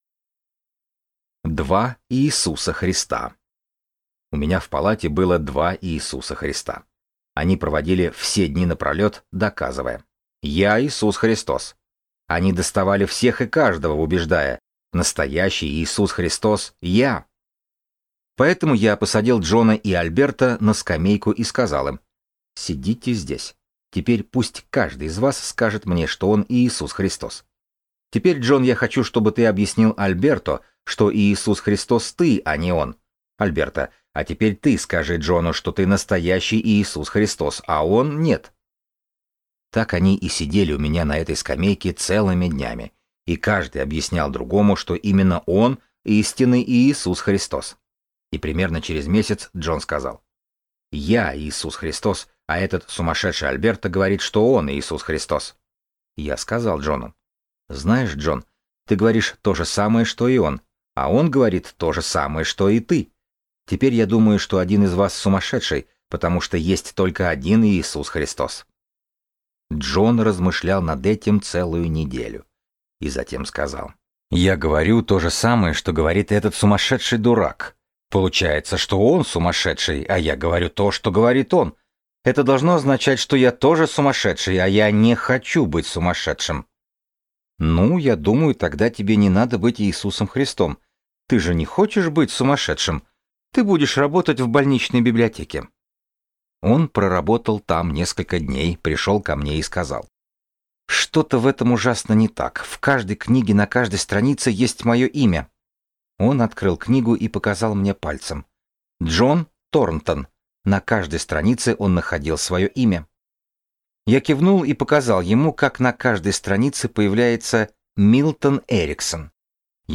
Аудиокнига Лечение пациентов с психозом | Библиотека аудиокниг